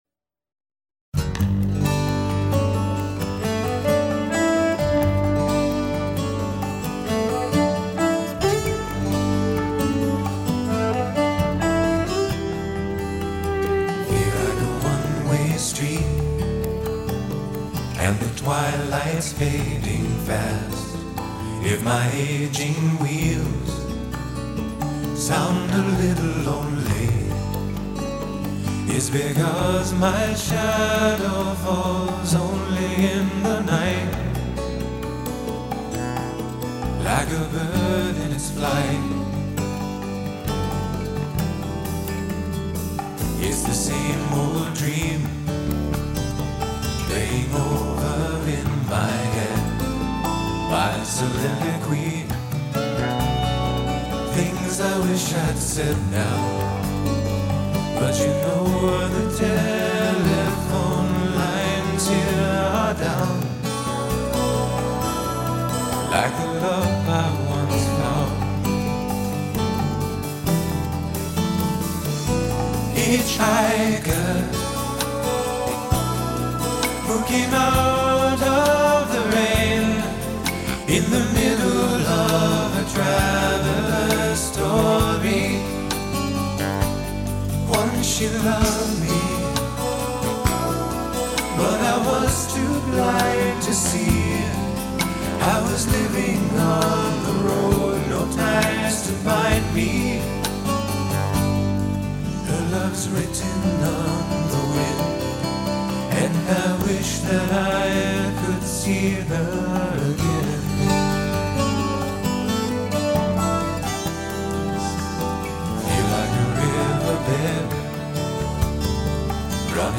本CD母带经美太平洋微音公司HDCD贰型处理器处理 24bit-96KHZ